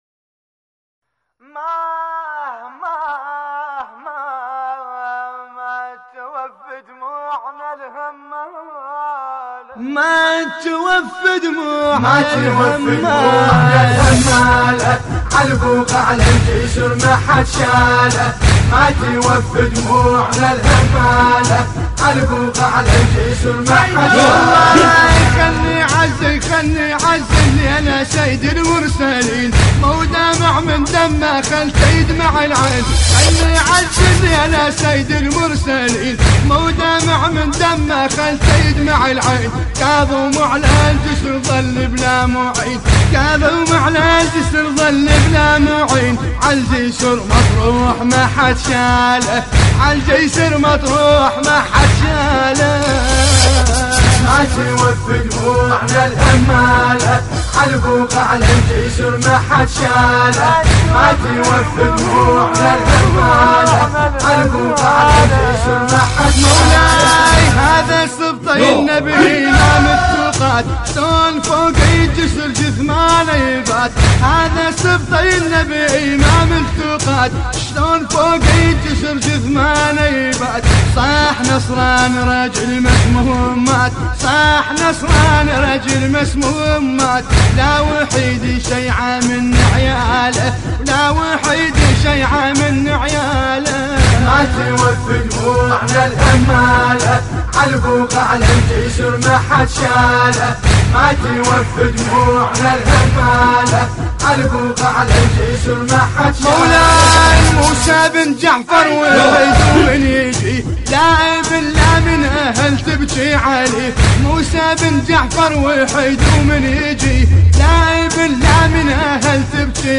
مراثي الامام الكاظم (ع)